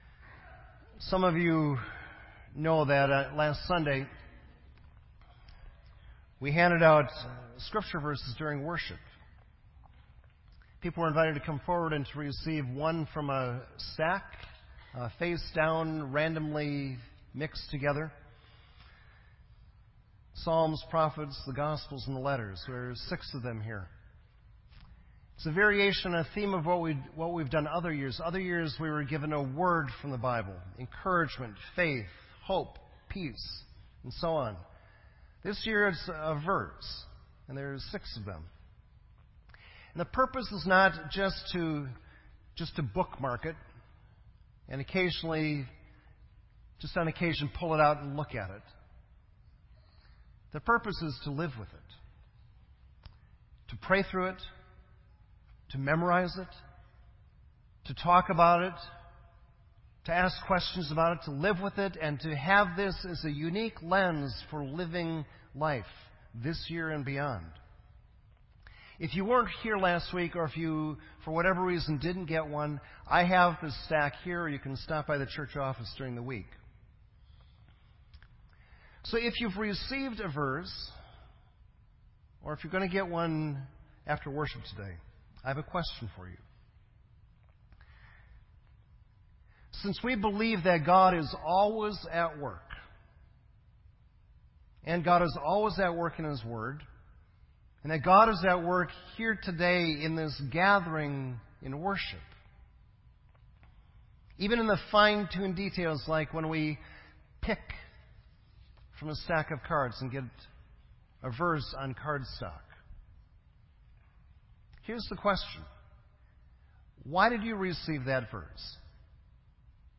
January 24, 2016 sermon